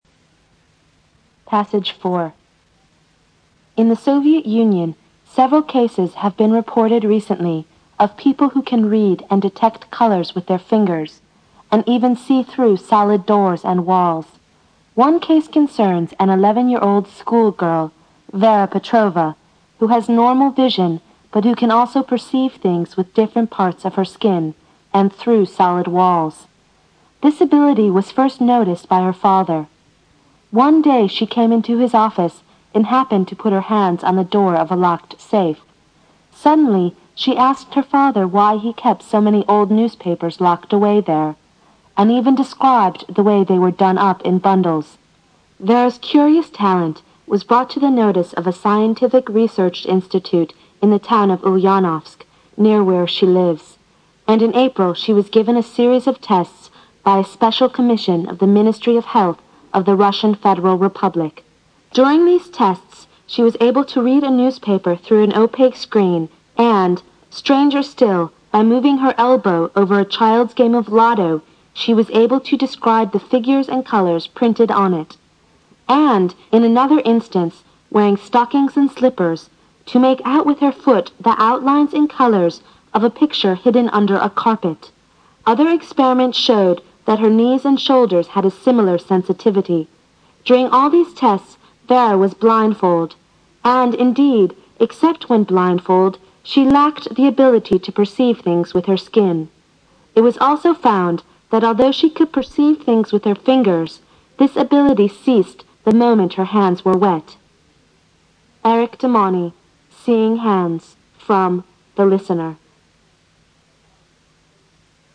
新概念英语85年上外美音版第四册 第4课 听力文件下载—在线英语听力室